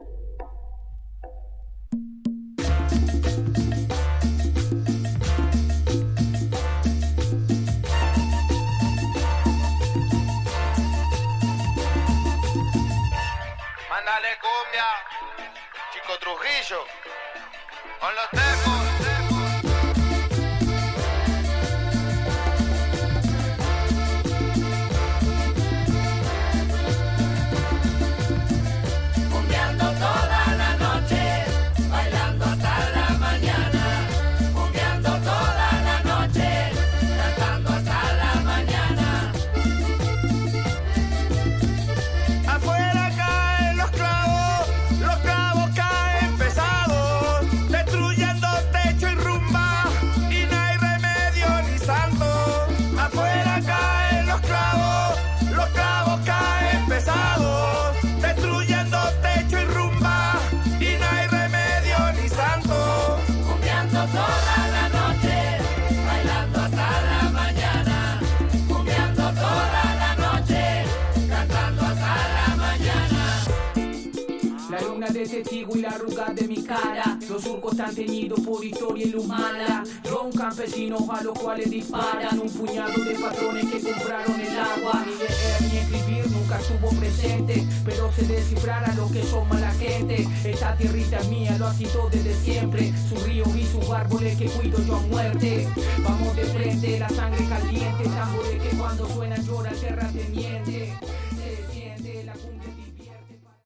Tags: Cumbia , Argentina